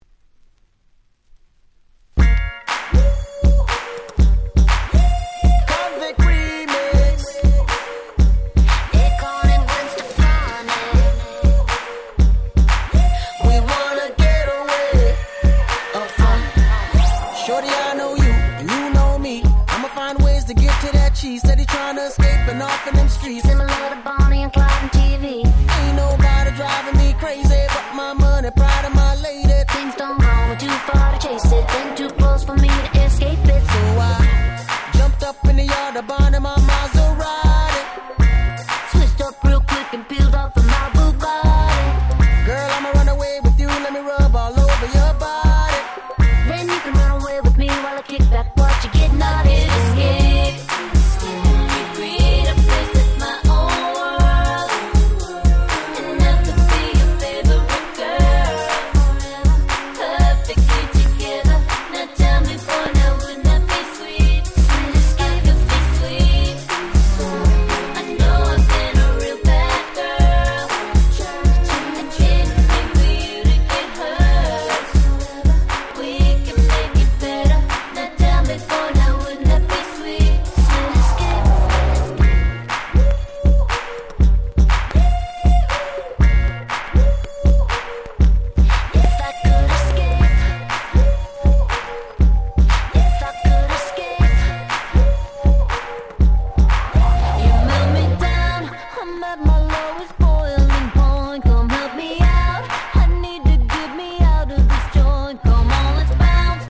07' Super Hit R&B / Pops !!